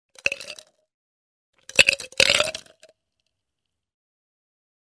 Throw ice dalam cawan plastik: